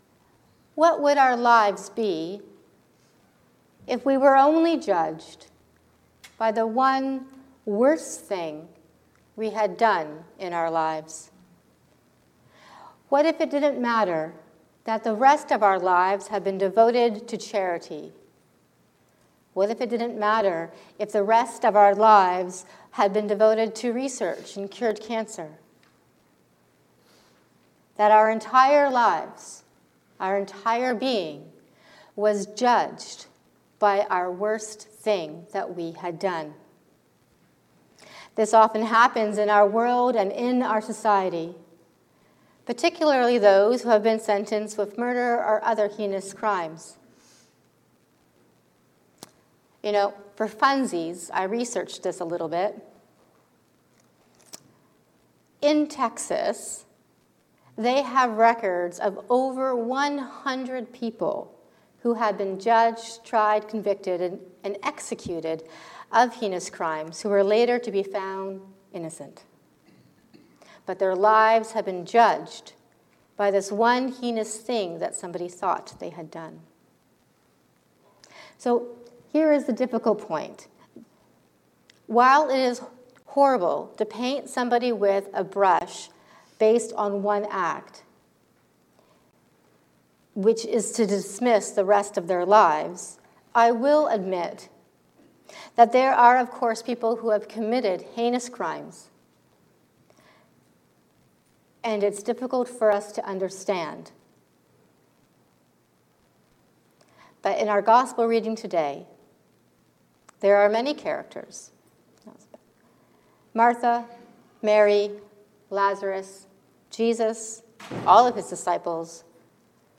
Consider Judas. A sermon for the Fifth Sunday in Lent